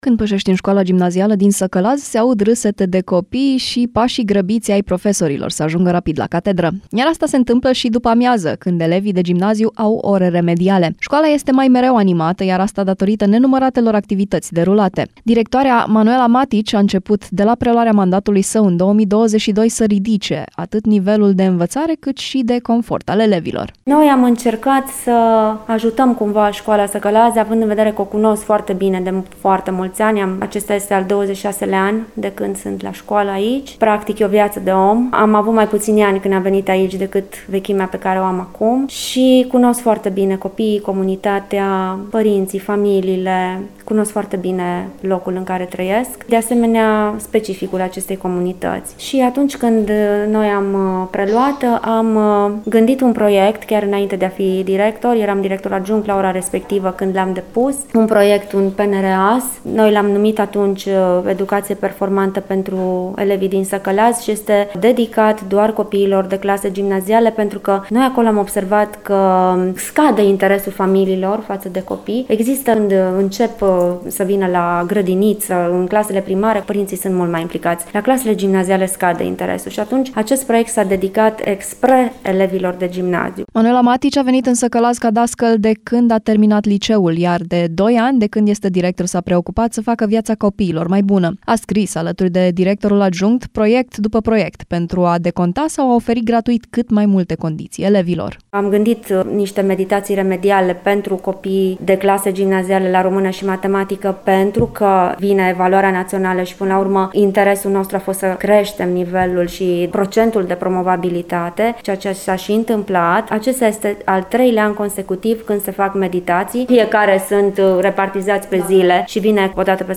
REPORTAJ | Povestea școlii din Timiș unde copiii vin bucuroși la cursuri.
Când pășești în Școala Gimnazială din Săcălaz, se aud râsete de copii și pașii grăbiți ai profesorilor, să ajungă rapid la catedră.